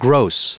Prononciation du mot gross en anglais (fichier audio)
Prononciation du mot : gross